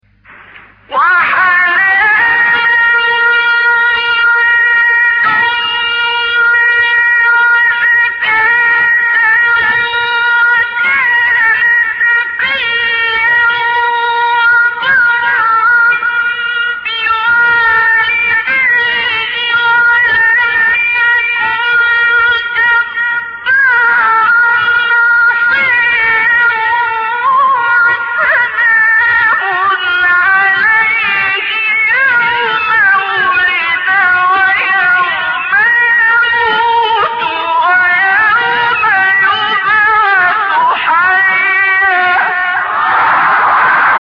سایت-قرآن-کلام-نورانی-حجاز-عبدالباسط-1.mp3